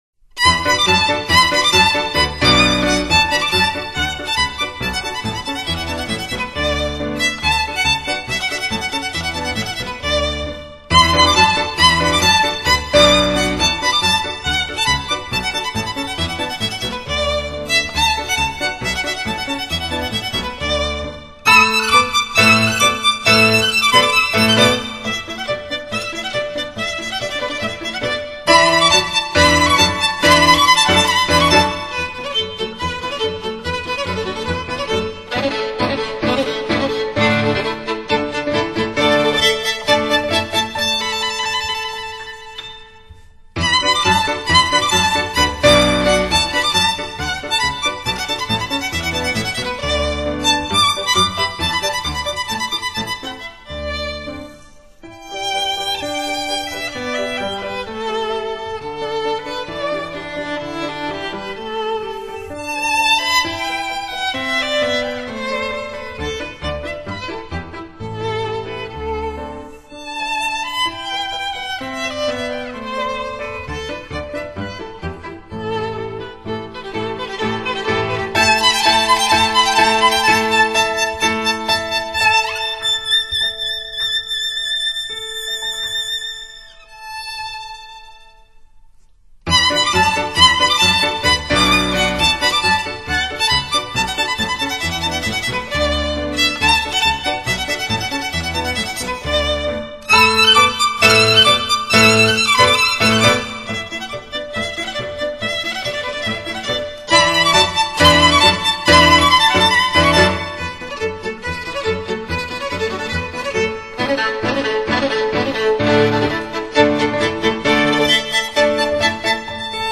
整部钢琴的琴音由头到尾的定位触手可及，琴音变化清晰无比，既通透明亮又顺耳，特别是中频的甜润和动态对比的凌厉简直让人惊讶。